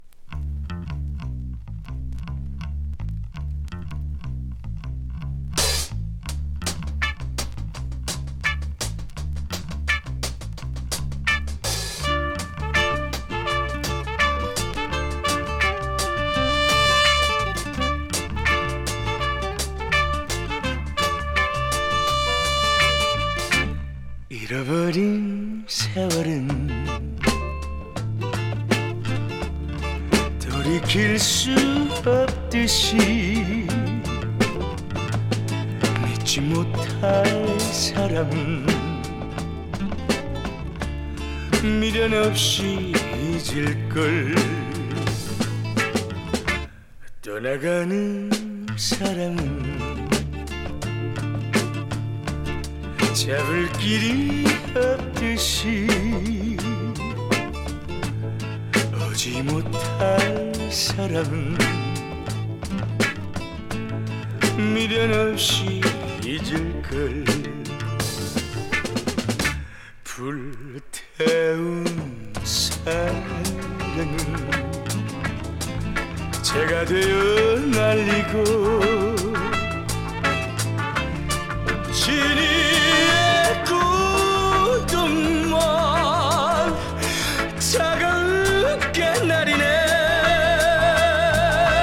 イントロから引き込まれる、スロウ・サイケ・ファンク！